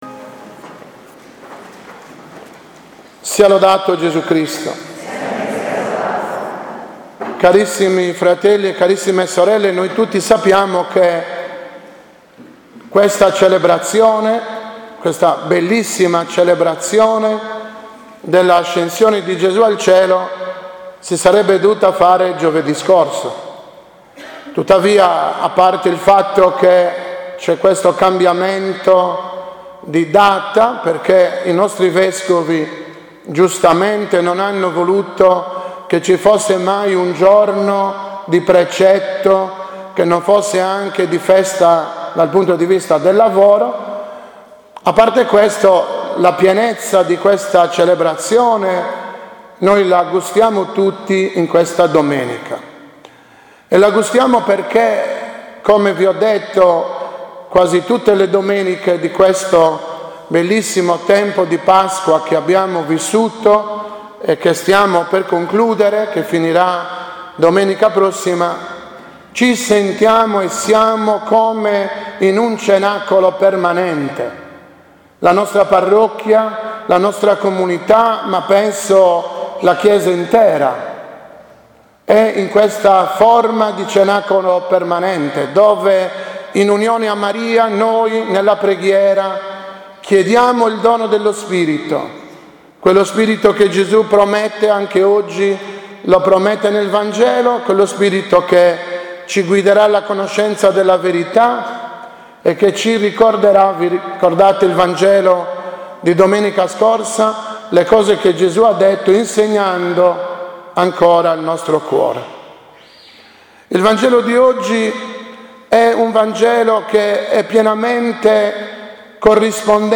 2019 OMELIA DELLA ASCENSIONE C